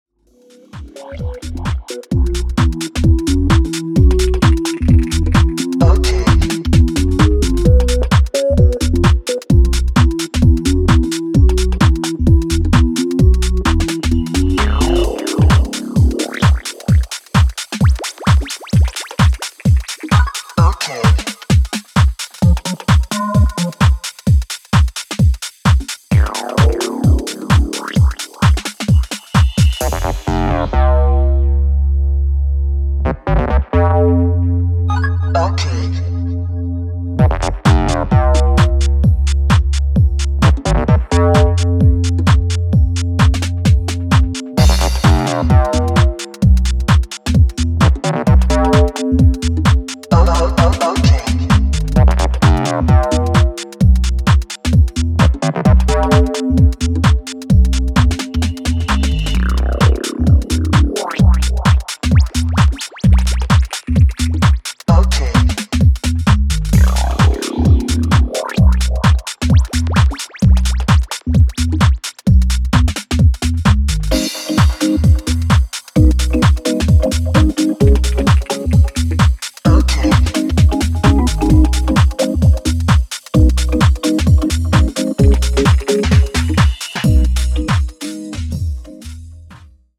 軽快な足回りのテック・ハウス4曲を収録
ストレンジなリフで遊びを効かせたベースライン・ハウスな